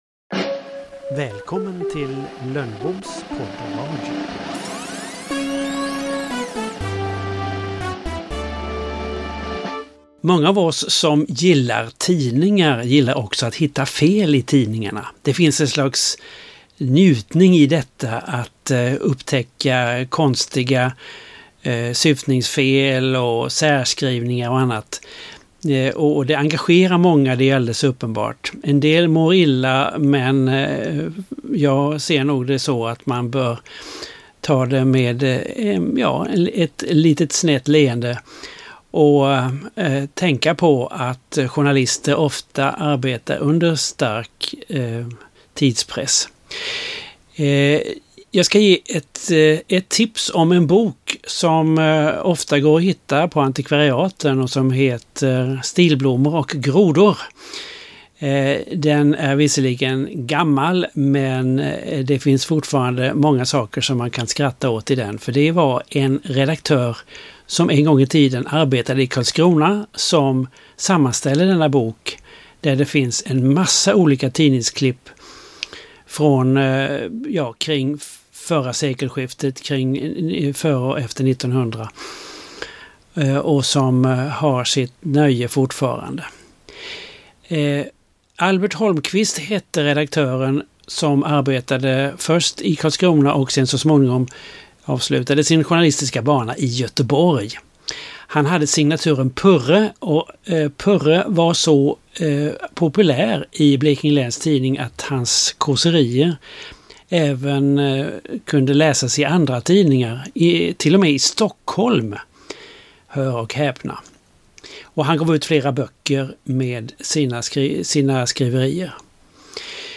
Källa: Wikipedia Bild: Flickr/commons En verklig skepparhistoria, återberättad av redaktören Albert Holmkvist, signaturen Purre, i Blekinge läns tidning år 1890. Inläst av